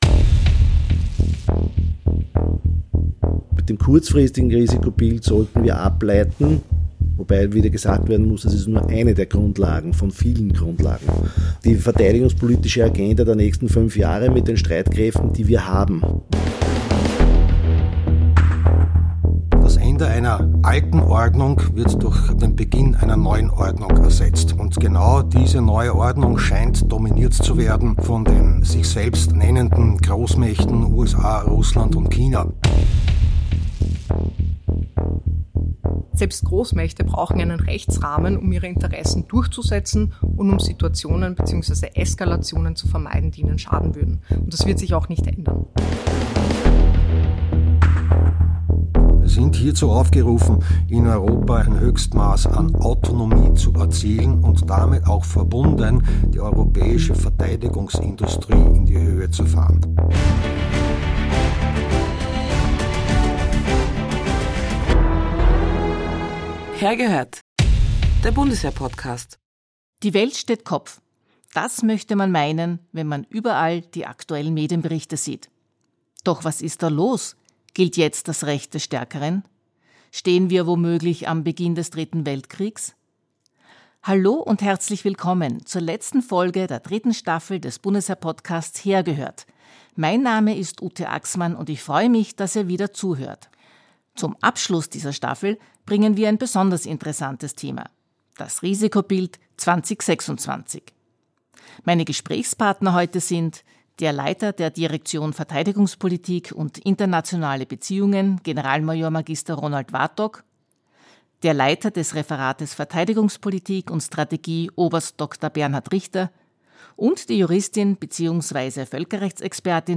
Im Gespräch erzählen sie von den Top-Risiken für das Jahr 2026, wie das Risikobild entstanden ist und erklären, was das für die regelbasierte Weltordnung bedeutet.